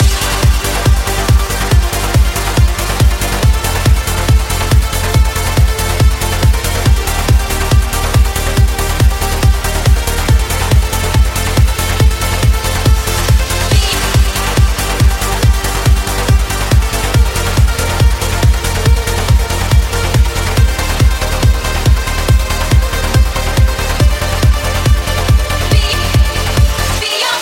uplifting trance
Genere: trance, uplifting trance